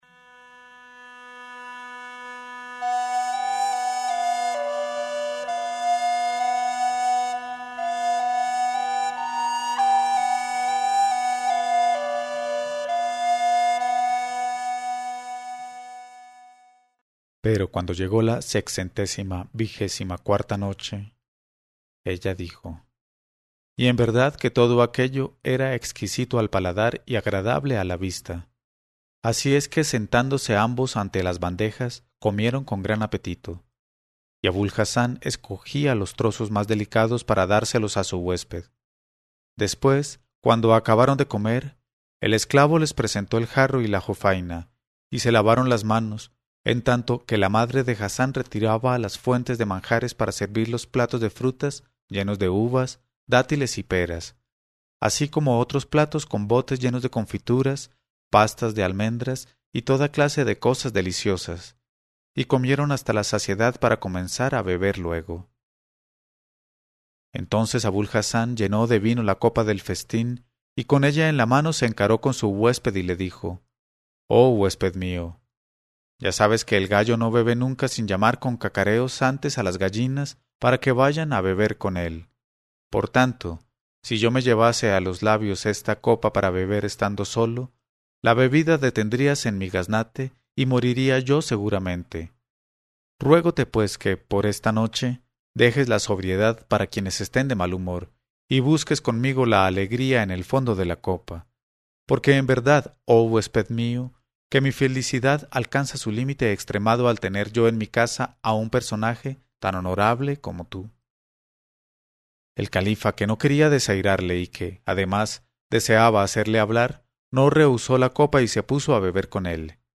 Una lectura en voz alta de Las mil noches y una noche. Una noche a la vez, "hasta que Schehrezada vea aparecer la mañana y calle discretamente".